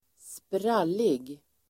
Uttal: [²spr'al:ig]